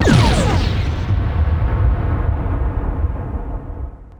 disruptor.wav